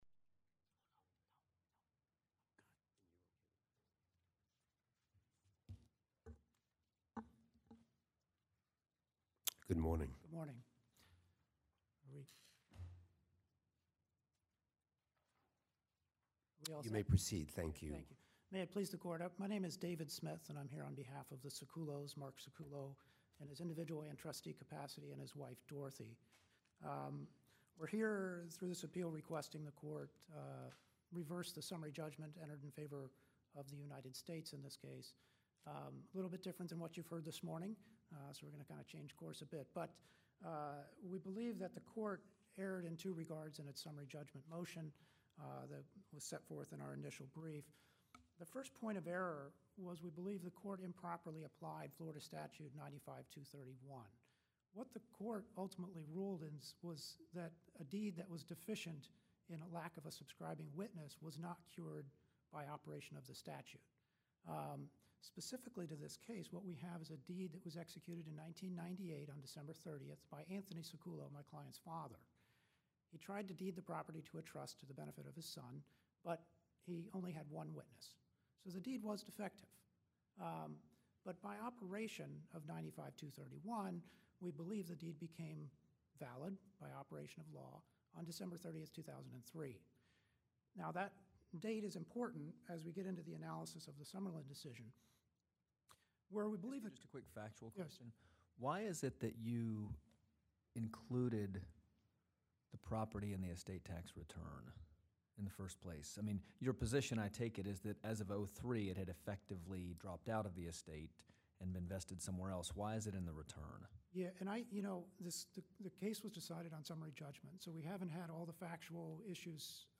Oral Argument Recordings | United States Court of Appeals